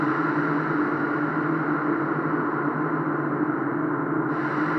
Index of /musicradar/sparse-soundscape-samples/Creep Vox Loops
SS_CreepVoxLoopB-09.wav